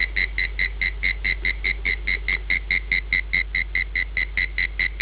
Males produce songs and build burrows to amplify their love song.
Habits : sings loudly in the wet summer night
Name : Common Mole Cricket - Gryllotalpa pluvialis, male
MoleCricket.au